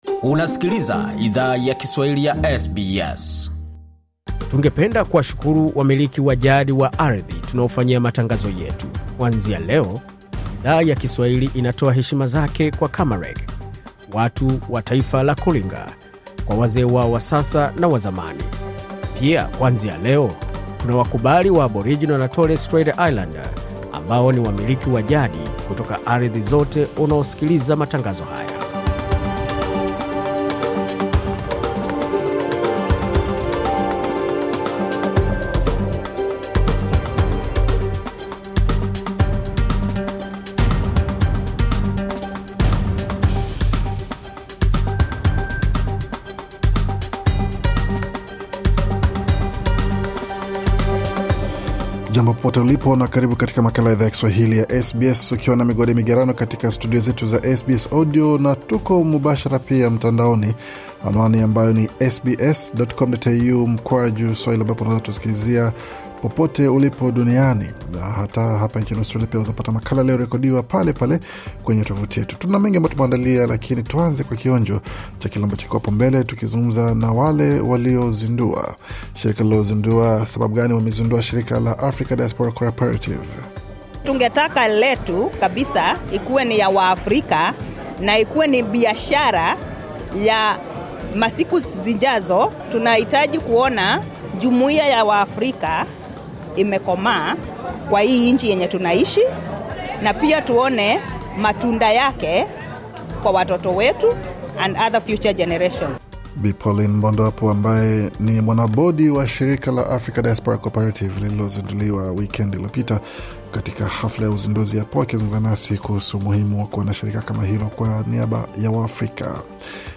Taarifa ya Habari 29 Julai 2025